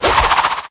shuriken-cast.ogg